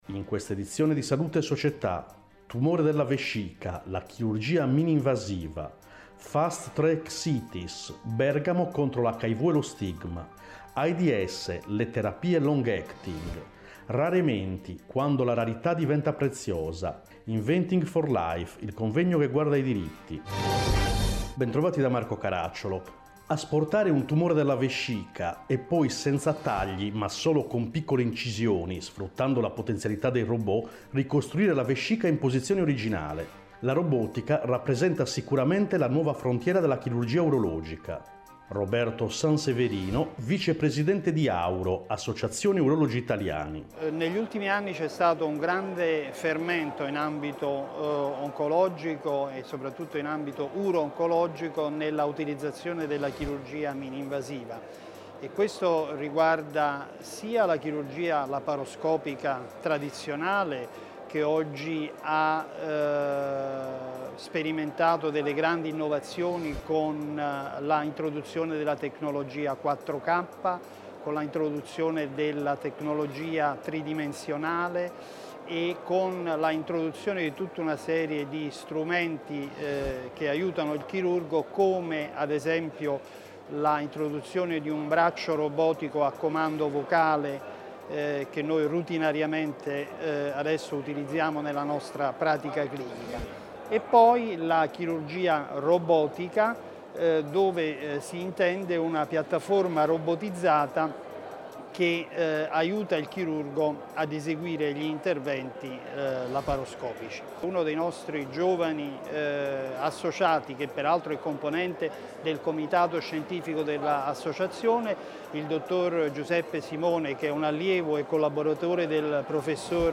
In questa edizione: 1. Tumore della vescica, Chirurgia mininvasiva 2. Fast-Track Cities, Bergamo contro l’HIV e lo stigma 3. Aids/Hiv, Terapie long acting 4. RareMenti, Quando la rarità diventa preziosa 5. Inventing for Life, Il convegno che guarda ai diritti Interviste